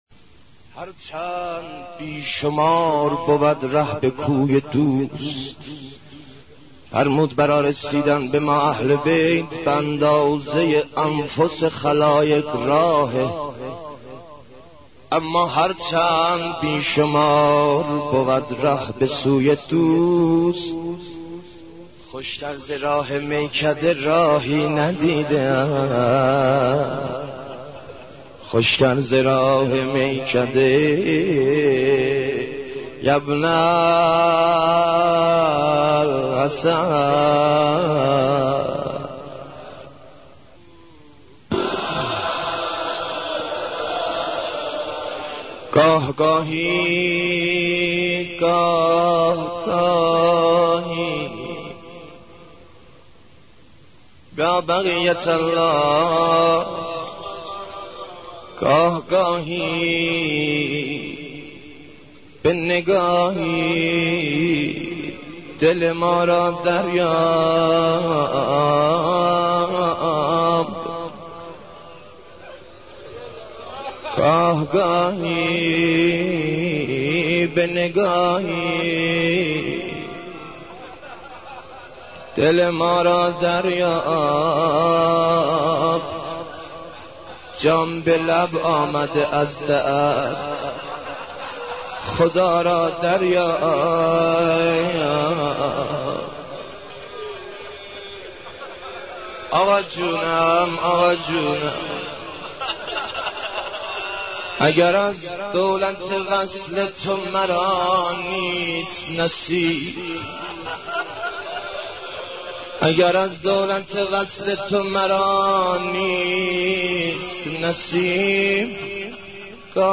حضرت عباس ع ـ مداحی 3